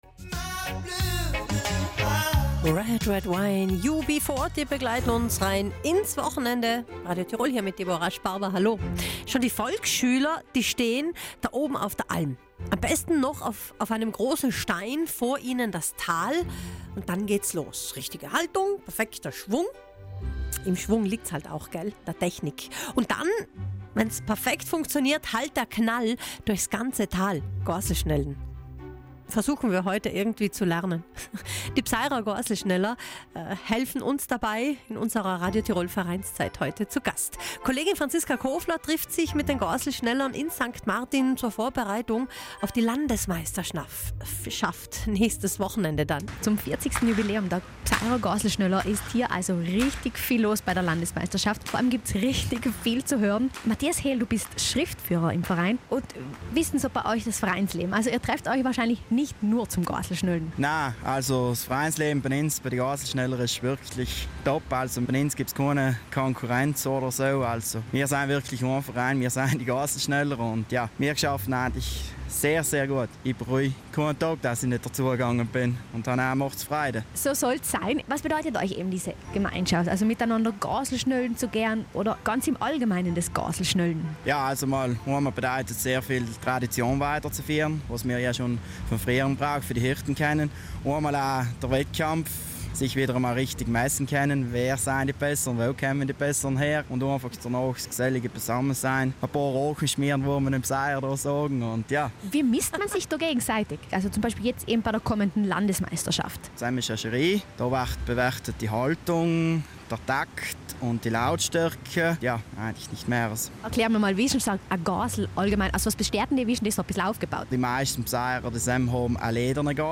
Mitschnitt_RT_Verein_Goaslschnoller.MP3